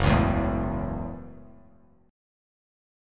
F#m7 chord